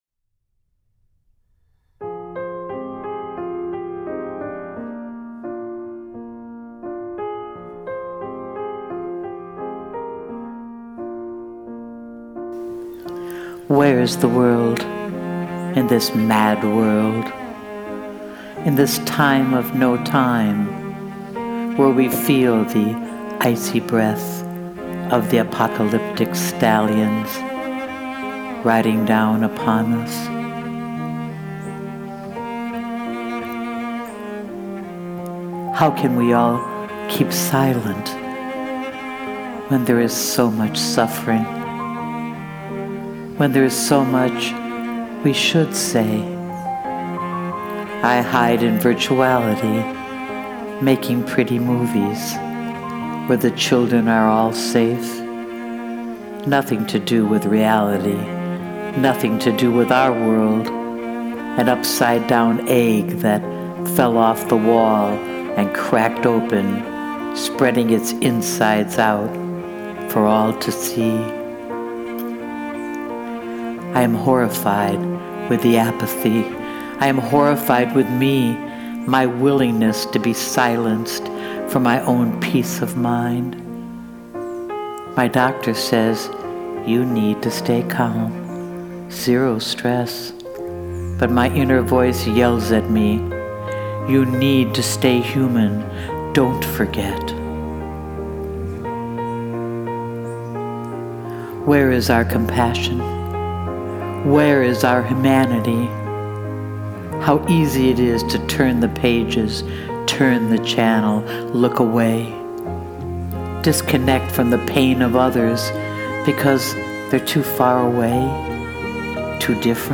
Please Click Play to hear me recite my poem to the music “Mad World” an instrumental cover
your words and music choice are superb